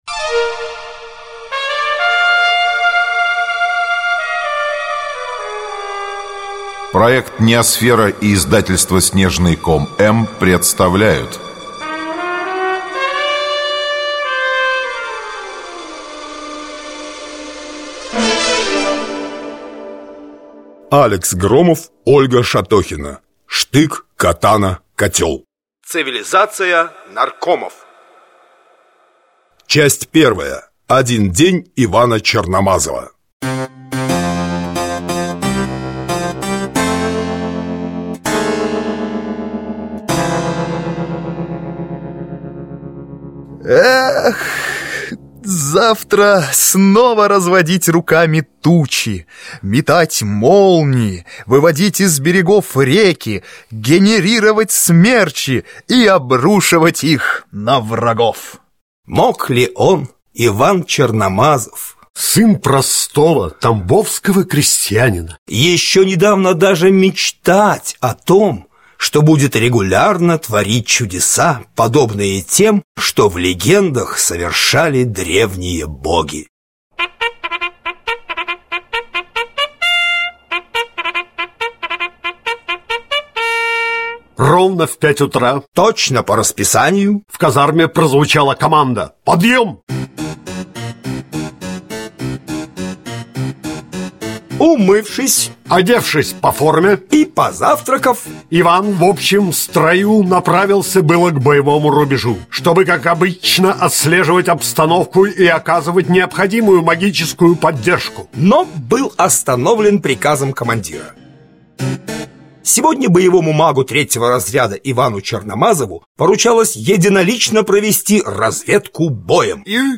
Аудиокнига Штык, катана, котел | Библиотека аудиокниг
Aудиокнига Штык, катана, котел Автор Ольга Шатохина Читает аудиокнигу Арт-группа NEOСФЕРА.